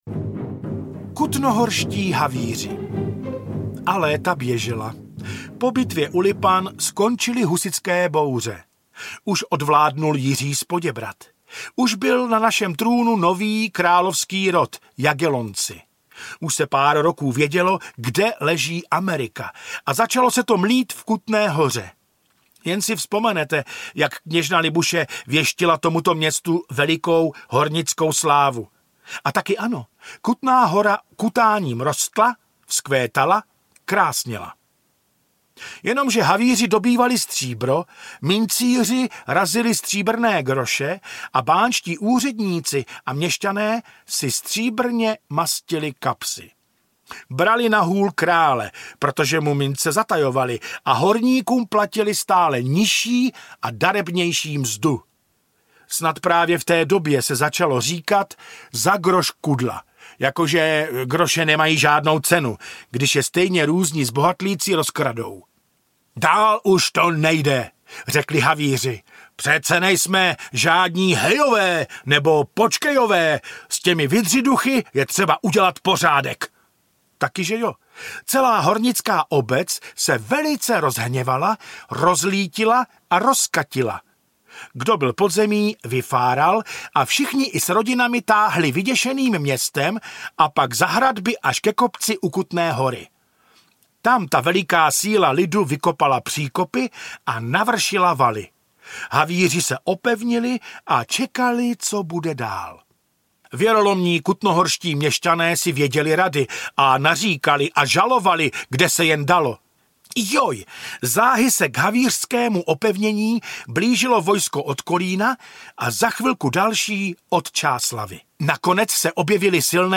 Staré pověsti české audiokniha
Ukázka z knihy